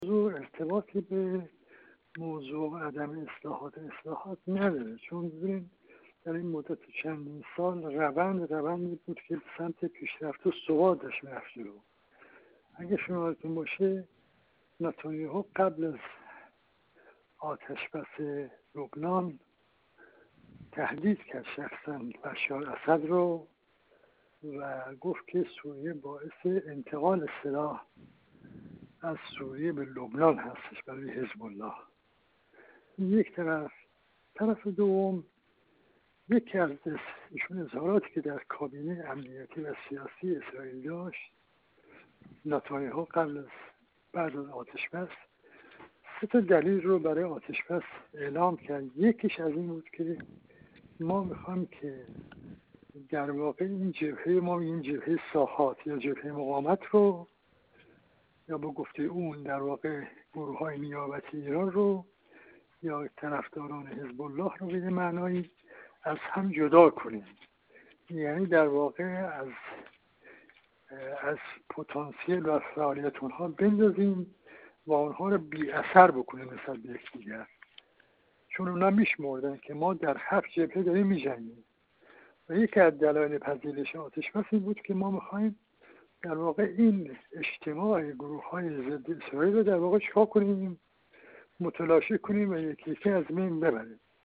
سیدرضا میرابیان، سفیر اسبق جمهوری اسلامی ایران در کویت و کارشناس ارشد مسائل غرب آسیا
سیدرضا میرابیان، سفیر اسبق جمهوری اسلامی ایران در کویت و کارشناس ارشد مسائل غرب آسیا، در گفت‌وگو با ایکنا درباره بحران اخیر سوریه پس از آتش‌بس لبنان و نقش عوامل داخلی در بروز این بحران گفت: این تنش ارتباطی با موضوع عدم اصلاحات اقتصادی و اجتماعی و سایر موارد در داخل سوریه ندارد.